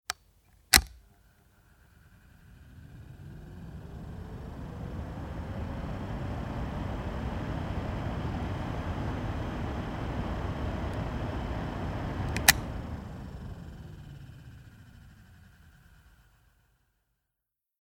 Tischlüfter "HL1"
Stufe 1